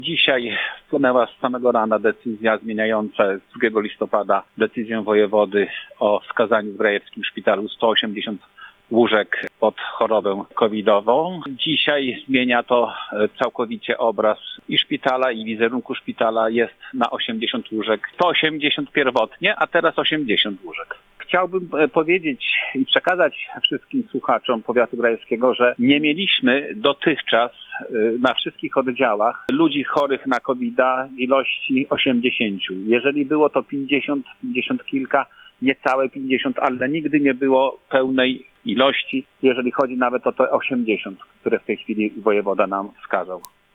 Waldemar Remfeld, starosta powiatu grajewskiego dodaje, że najwięcej dotychczas było ich zajętych nieco ponad 50.